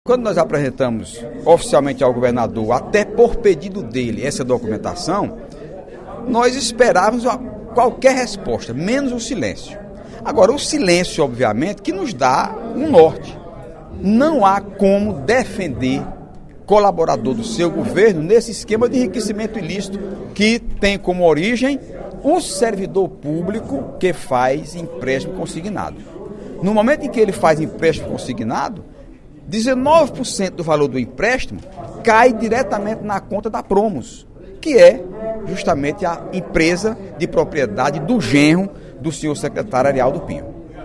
O deputado Heitor Férrer (PDT) voltou a criticar, na sessão plenária desta terça-feira (27/03) da Assembleia Legislativa, o modelo de empréstimos consignados do Governo do Estado.